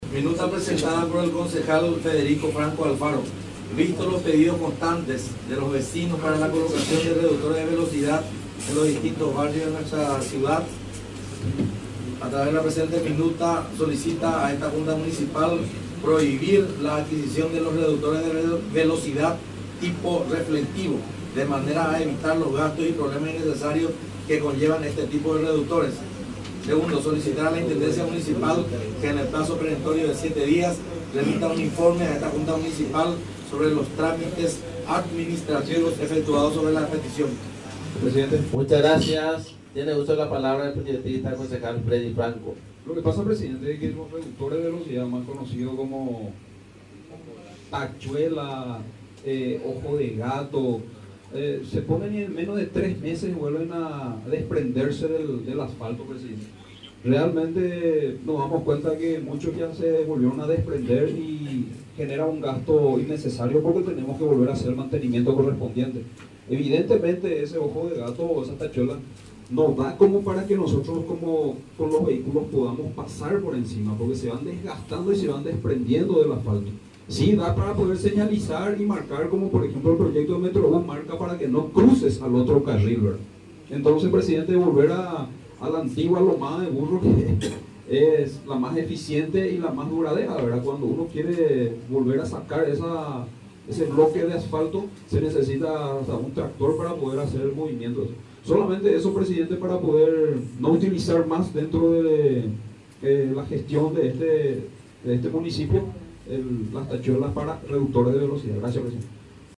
El pasado miércoles durante la sesión ordinaria de la Junta Municipal uno de los concejales pidió que se prohíba la colocación de reductores de velocidad (de goma con reflectores) debido a que no tienen mucha duración y resulta ser un gasto innecesario, a cambio pide volver a la colocación de las “lomadas” ya que es la más eficiente y más duradera.
Audio de la presentacion y fundamento de la minuta